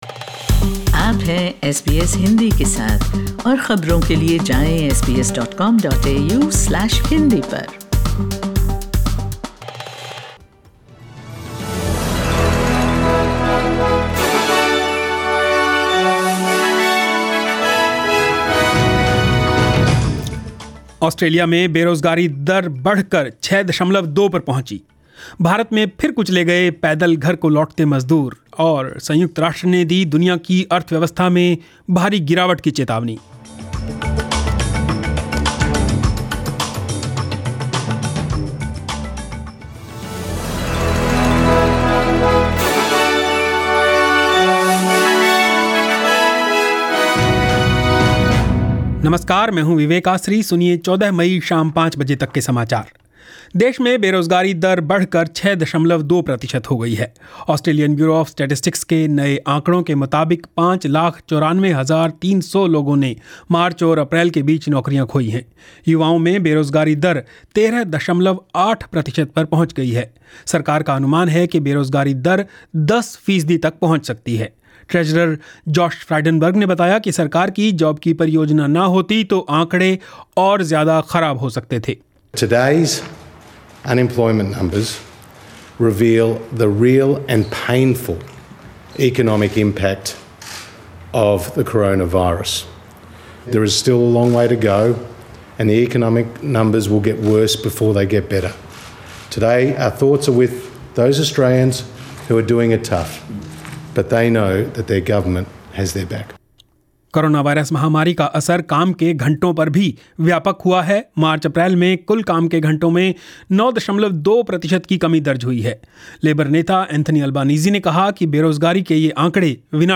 News in Hindi 14 May 2020